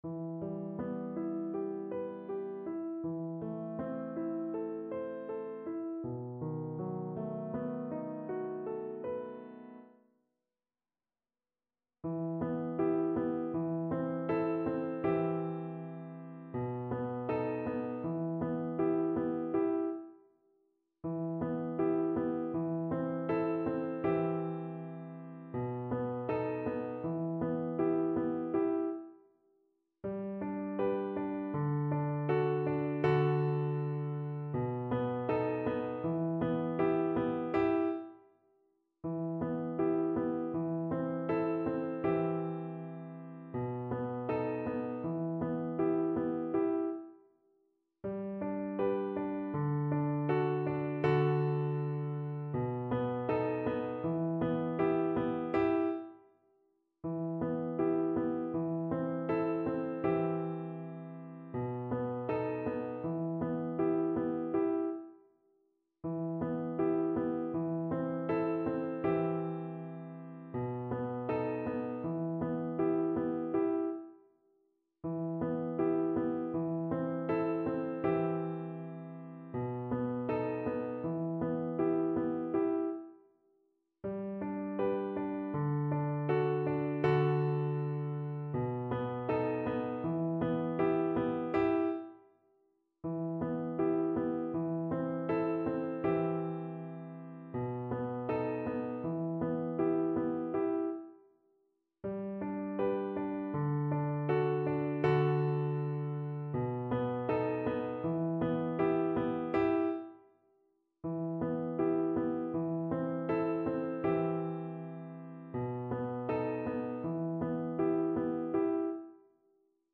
kolęda: Nie było miejsca dla ciebie (na skrzypce i fortepian)
Symulacja akompaniamentu